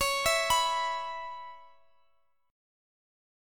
Listen to E5/C# strummed